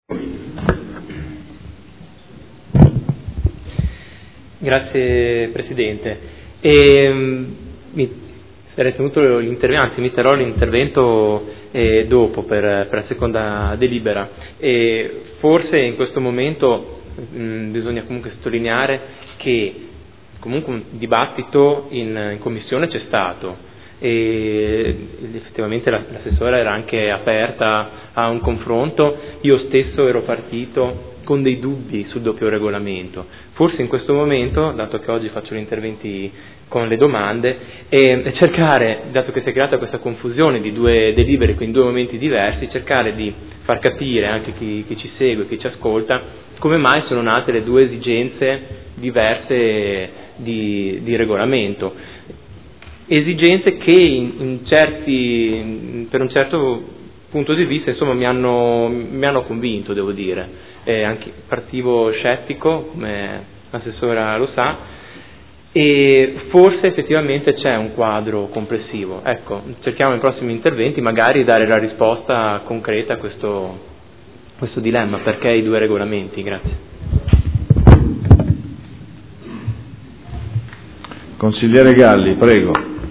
Seduta del 23/07/2015 Delibera. Regolamento di assegnazione e gestione degli orti per anziani – Schema assegnazione dell’orto – Approvazione modifiche.